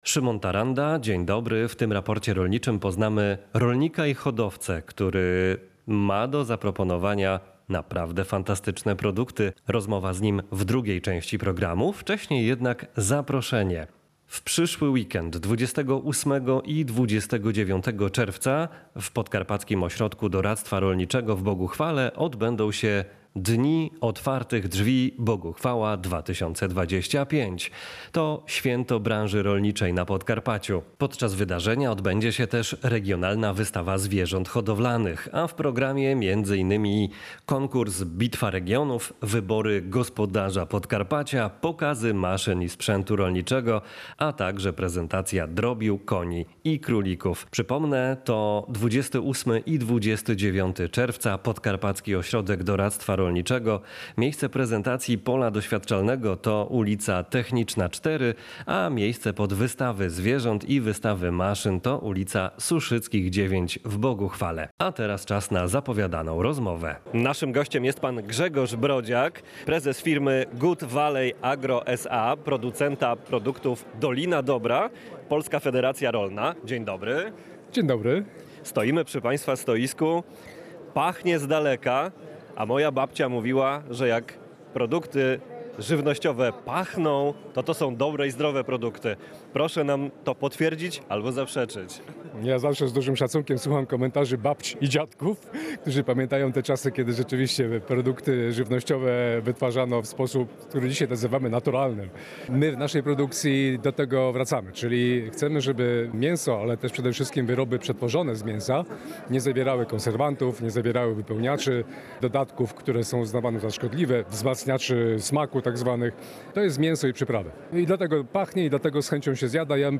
Właśnie o żywności ekologicznej rozmawiamy w tym wydaniu „Raportu rolniczego”.